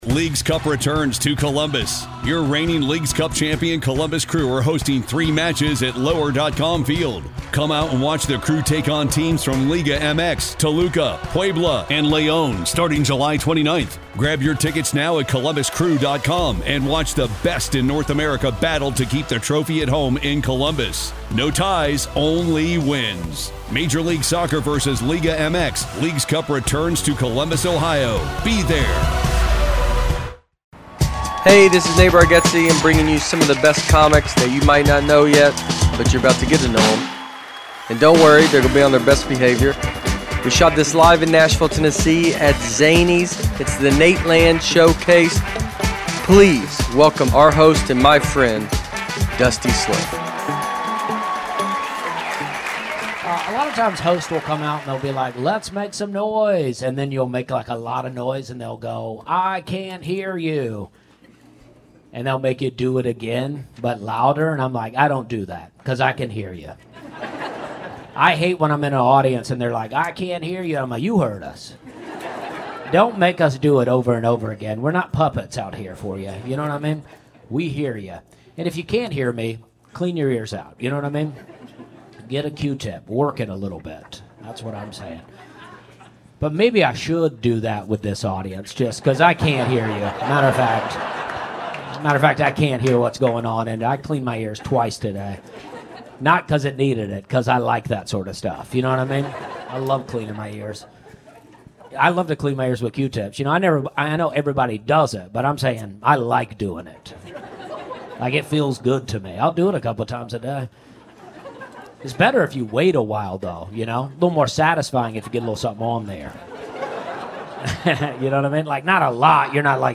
Nateland Presents The Showcase. The Showcase features several of the best comics that you might not know yet, but you're about to get to to know 'em. Hosted by the members of The Nateland Podcast and directed by Nate Bargatze himself, The Showcase was recorded live at Zanies Comedy Club in Nashville, Tennessee. Standup comedy at its best.